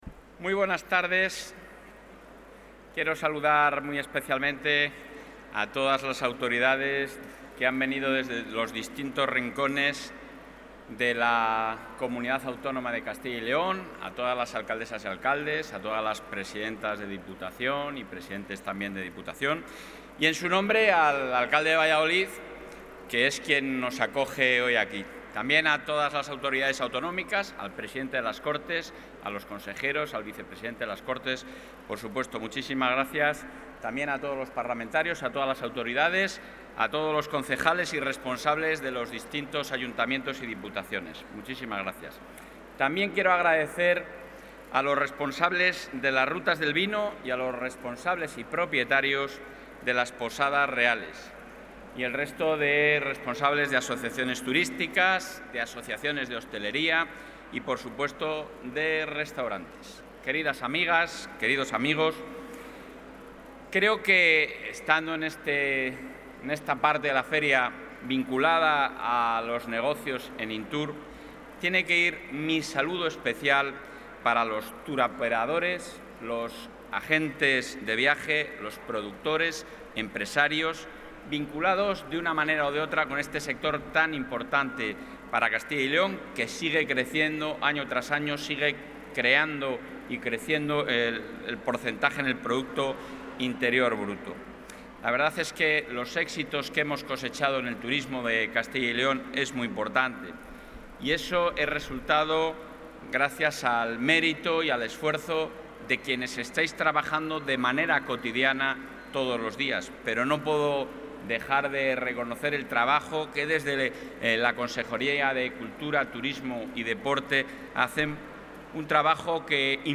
Intervención del presidente de la Junta.
El presidente de la Junta de Castilla y León, Alfonso Fernández Mañueco, ha presentado hoy en Valladolid la Campaña de Promoción Turística de Castilla y León, dentro del marco de la Feria Internacional de Turismo de Interior, INTUR.